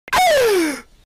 senior pelo original gasp sound Meme Sound Effect
senior pelo original gasp sound.mp3